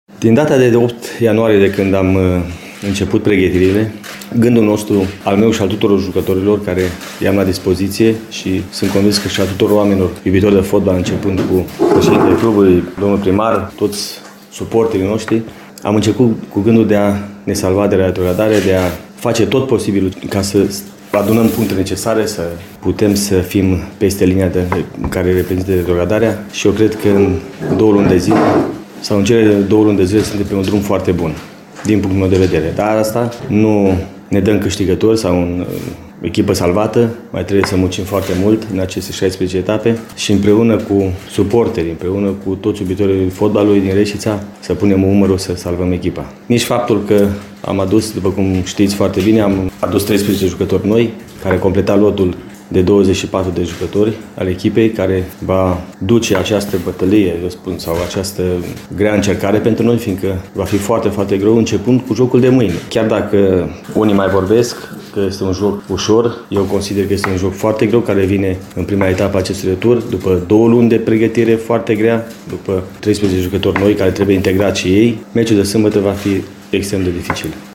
Gorjenii au mari probleme de lot și financiare, însă antrenorul bănățenilor, Dorinel Munteanu, este circumspect: